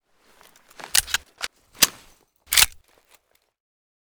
fn57_reload_empty.ogg